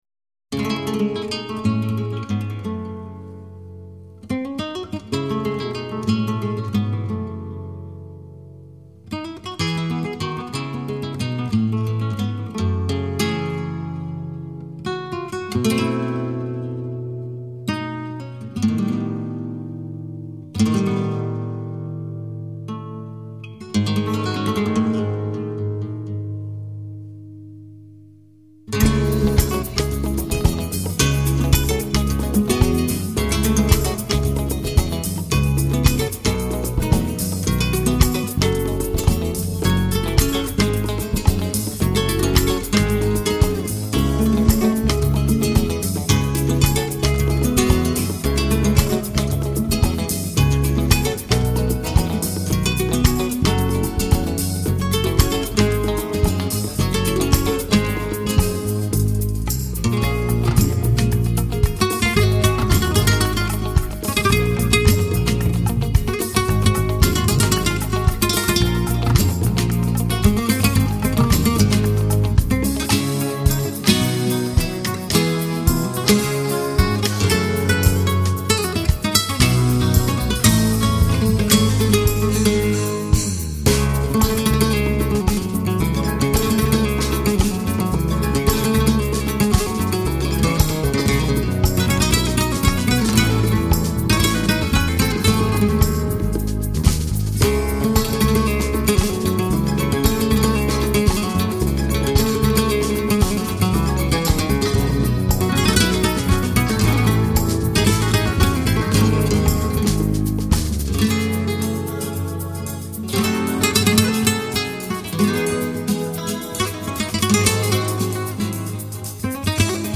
音乐风格: 拉丁